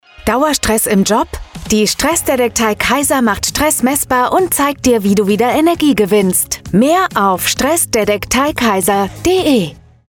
RPR1 Radio-Werbung